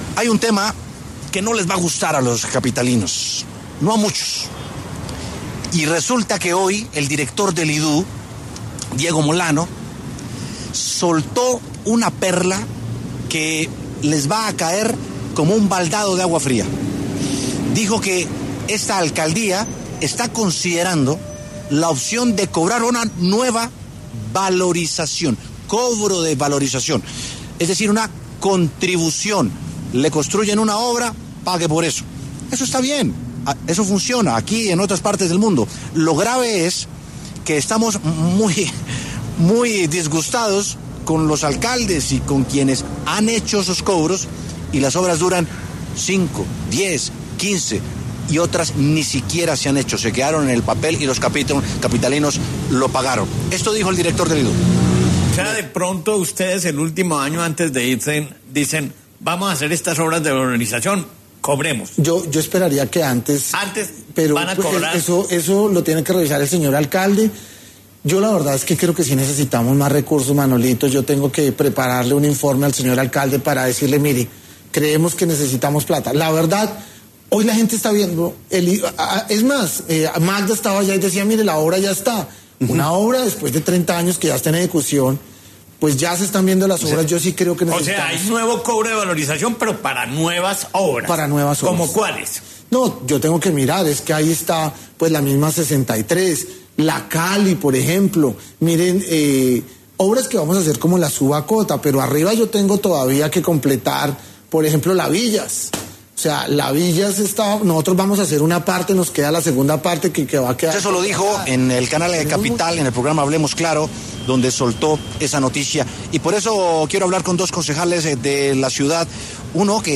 A propósito de esto, W Sin Carreta habló con los concejales Cristina Calderón y Daniel Briceño para conocer su punto de vista frente a estas declaraciones.